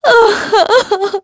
Veronica-Vox_Sad.wav